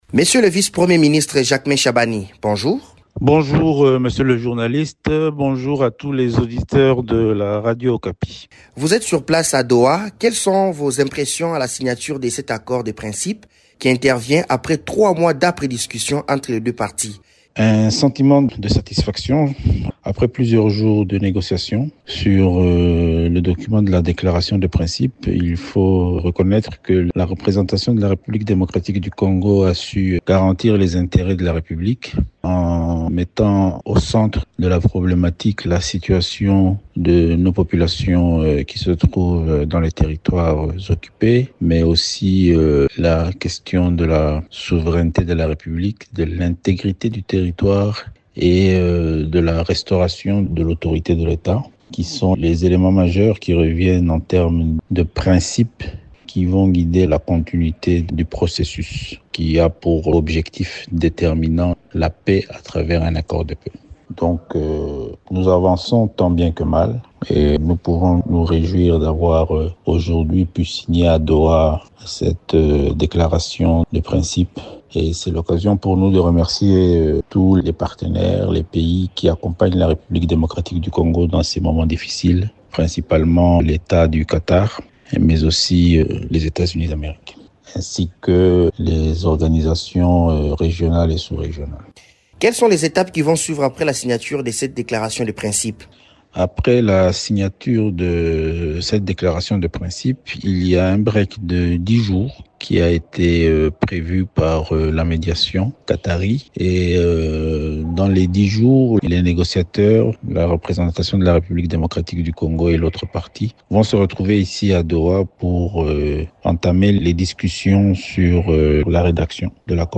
Invité de Radio Okapi, le représentant de la RDC à Doha, Jacquemain Shabani, se félicite du contenu de ce texte, qui, selon lui, garantit les intérêts fondamentaux de la République.